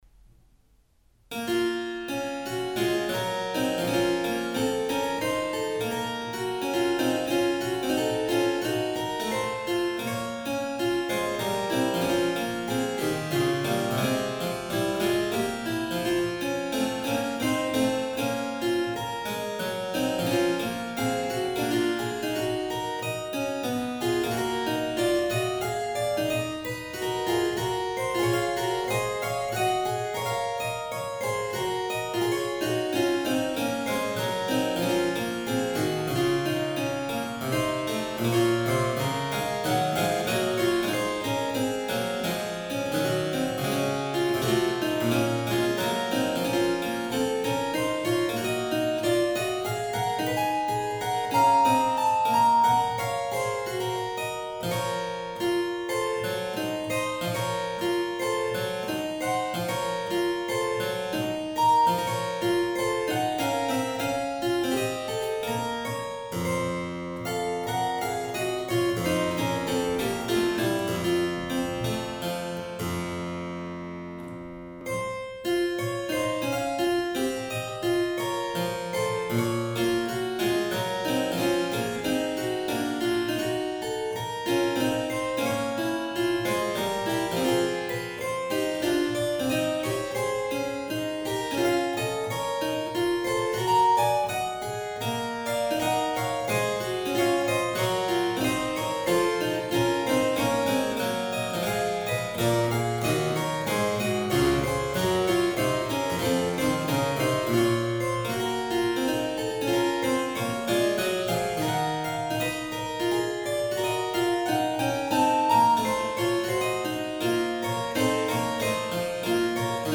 Clavicembalo in stile italiano (sec. XVII) realizzato nel 2009
clavicembalo